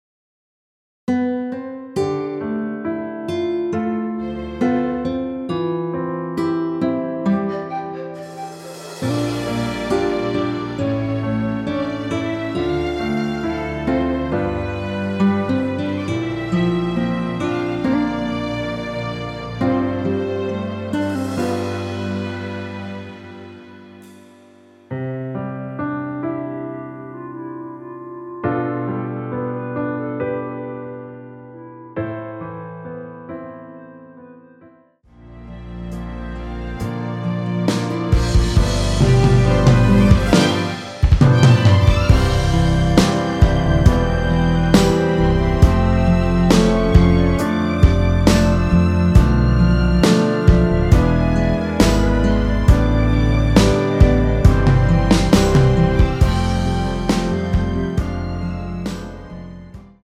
원키에서(-5)내린 멜로디 포함된 MR입니다.(미리듣기 확인)
앞부분30초, 뒷부분30초씩 편집해서 올려 드리고 있습니다.
중간에 음이 끈어지고 다시 나오는 이유는